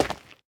Minecraft Version Minecraft Version 25w18a Latest Release | Latest Snapshot 25w18a / assets / minecraft / sounds / block / basalt / step2.ogg Compare With Compare With Latest Release | Latest Snapshot